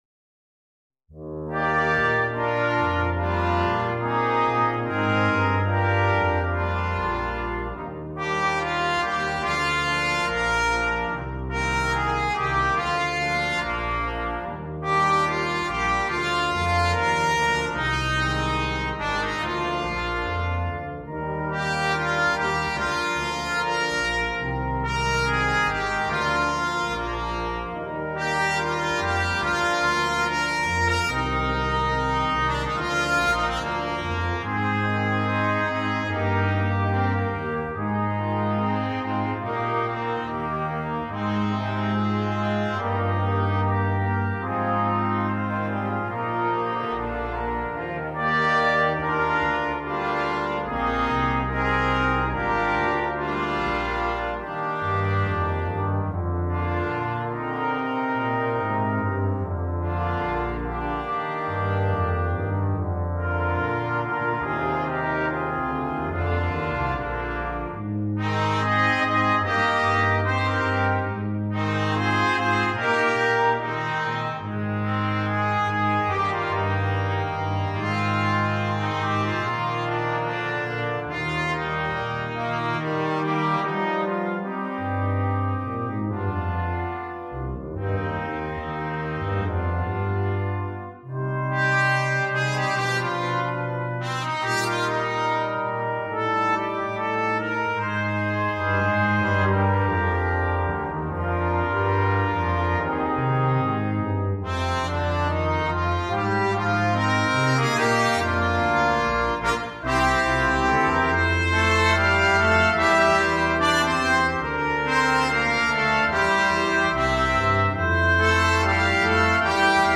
(for Brass Quintet)
arranged for Brass Quintet. This is an easy arrangement